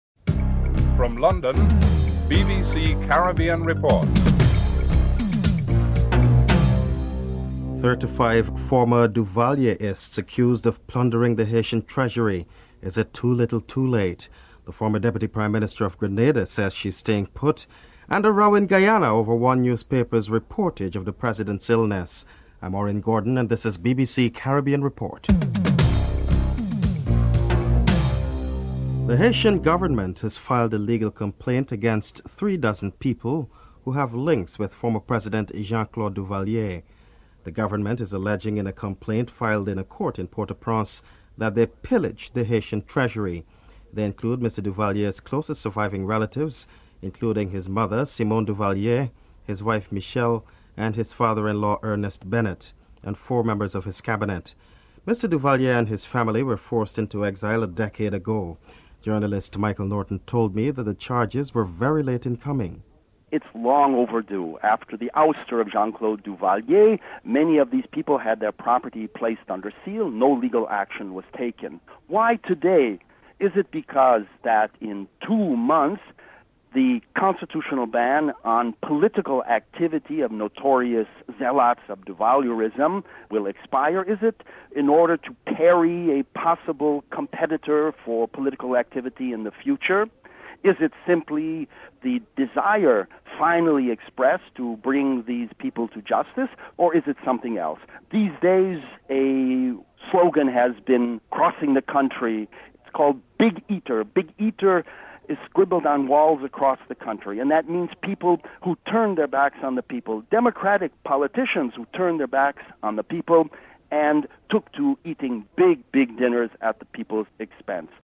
1. Headlines (00:00-00:27)
7. Nizam Mohammed is adamant that Dr. Vincent Lasse should vacate his seat in parliament. Nizam Mohammed, fromer Speaker of the house - Trinidad and Tobago and political leader of the National Alliance for Reconstruction is interviewed. (09:49:11:51)